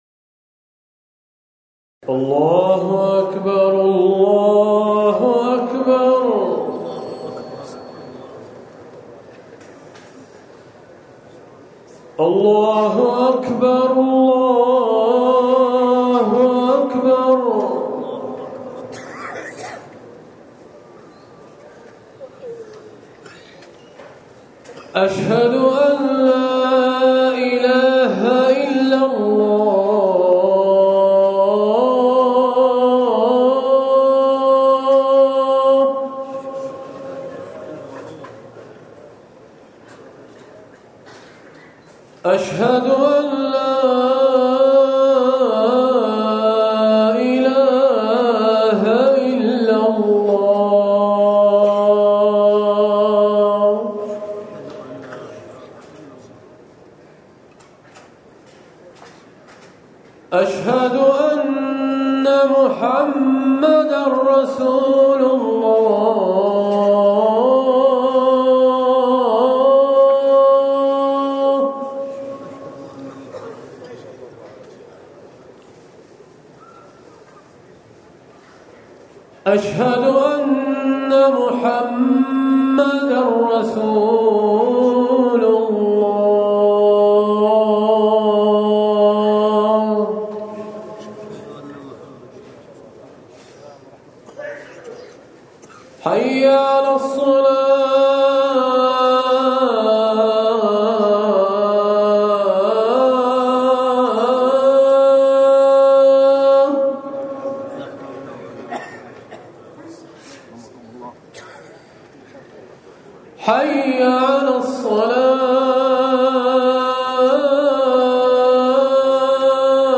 أذان العشاء
تاريخ النشر ٢ ربيع الأول ١٤٤١ هـ المكان: المسجد النبوي الشيخ